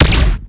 fire_bolt_short.wav